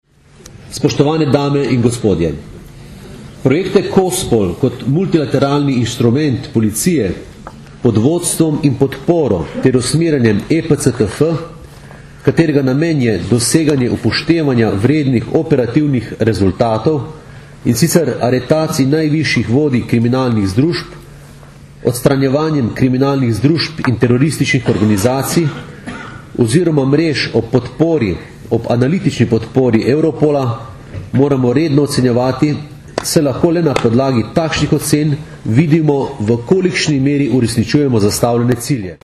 Zvočni posnetek izjave mag. Aleksandra Jevška (mp3)